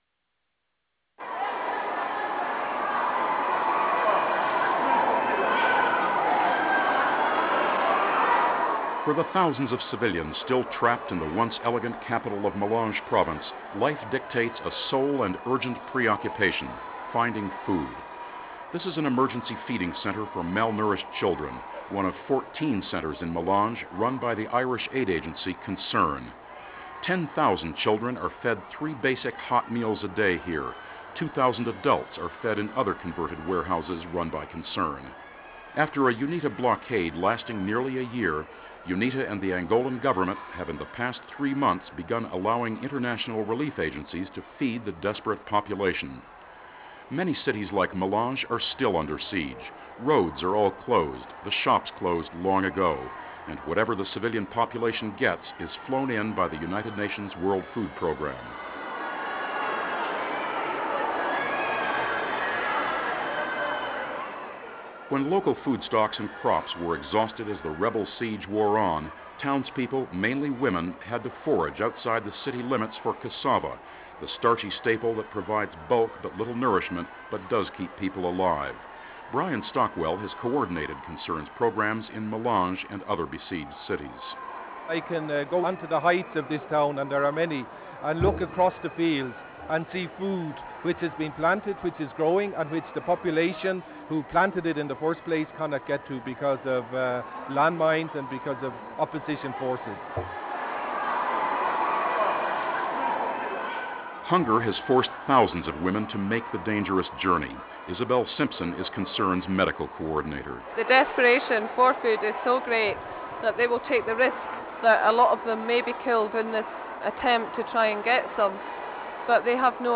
The report was produced by Monitor Radio and originally broadcast in February of 1994.